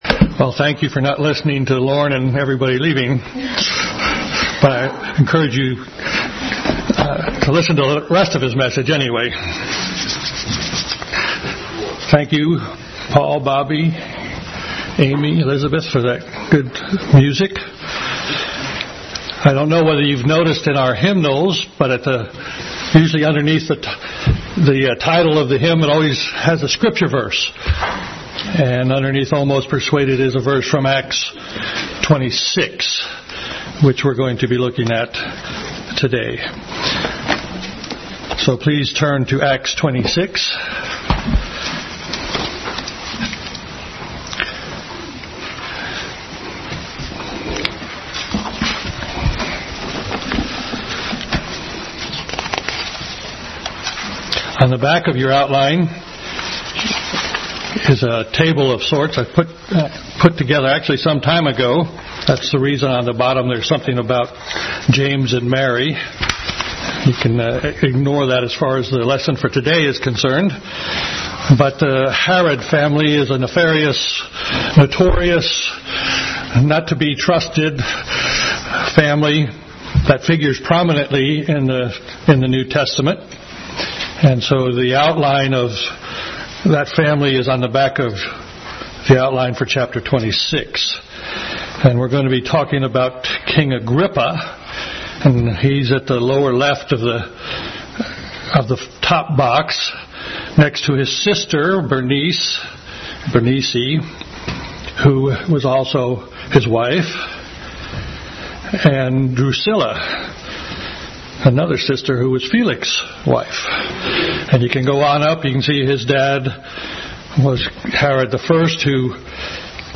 Acts 26:1-32 Service Type: Family Bible Hour Bible Text